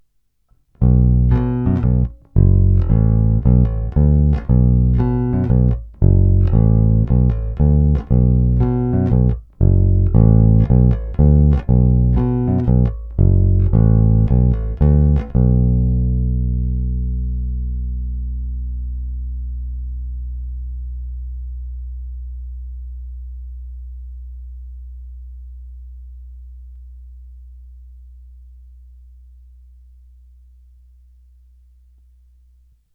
Agresívní, zvonivý, nicméně zde díky aktivní elektronice jej lze značně usměrnit a zkrotit.
Následující nahrávky jsou provedeny rovnou do zvukové karty a dále ponechány bez jakýchkoli úprav, kromě normalizace samozřejmě. Použité struny jsou neznámé niklové pětačtyřicítky ve výborném stavu.
Snímač u krku